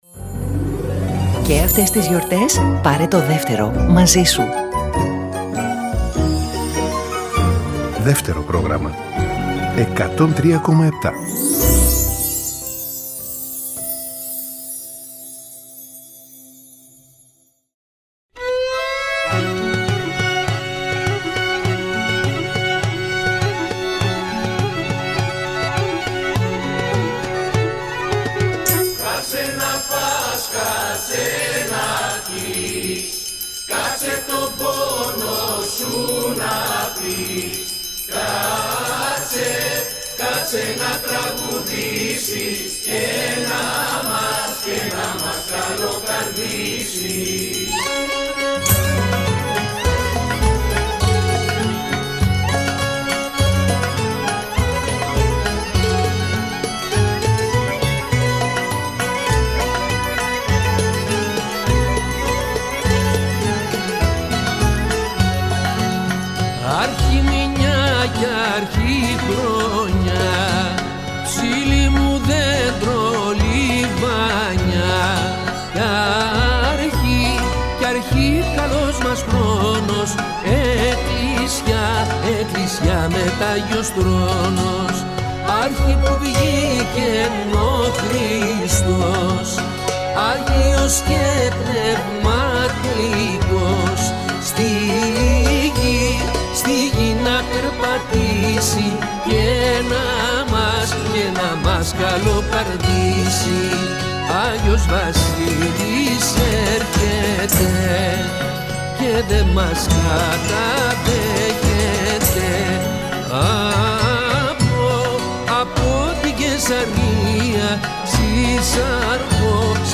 Διαβάζουν αγαπημένες φωνές